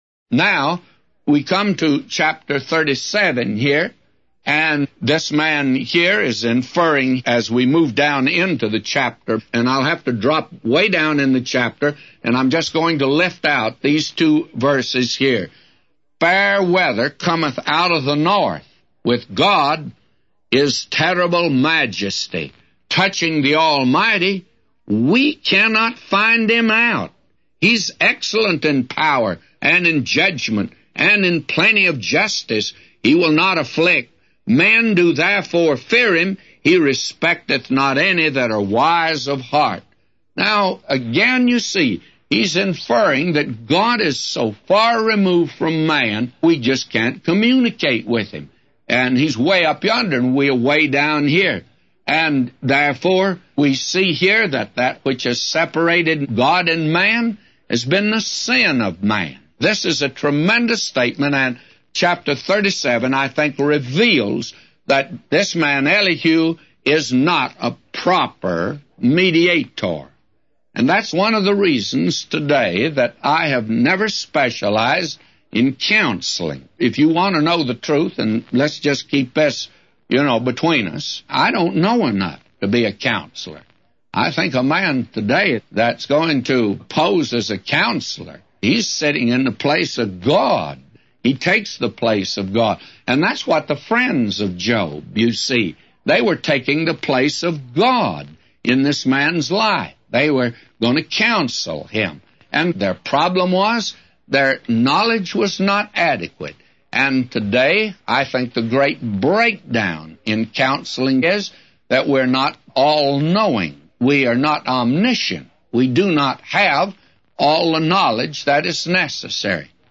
A Commentary By J Vernon MCgee For Job 37:1-999